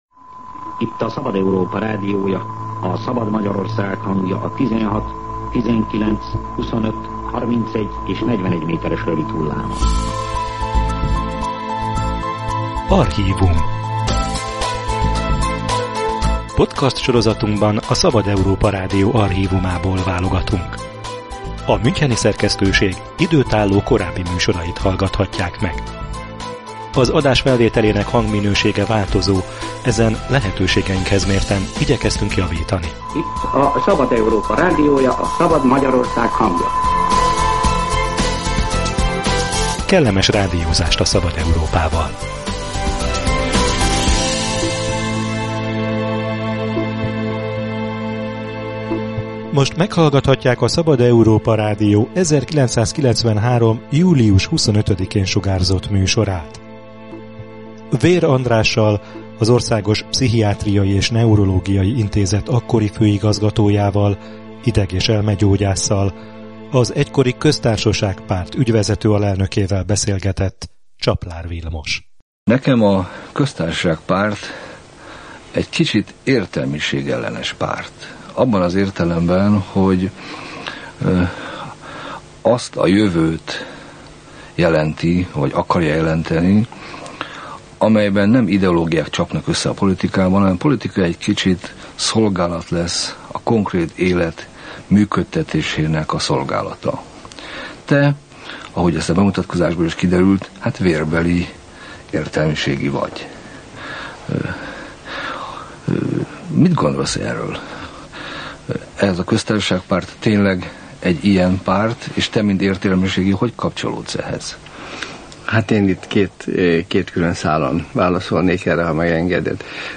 archív interjú